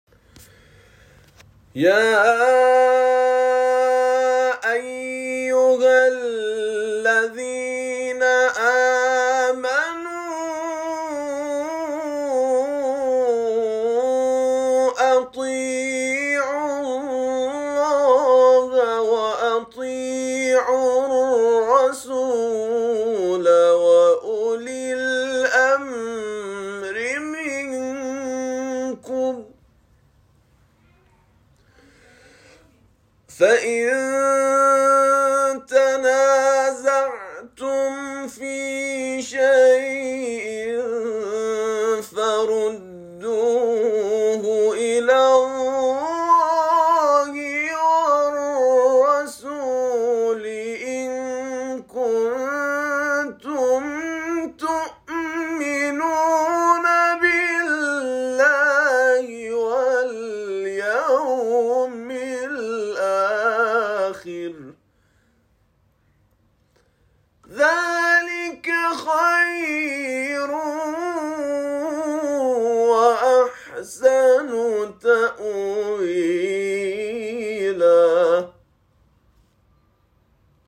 تلاوت نفرات برتر مسابقه از دید داوران:
تلاوت